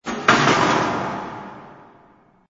sfx_syd_clang.wav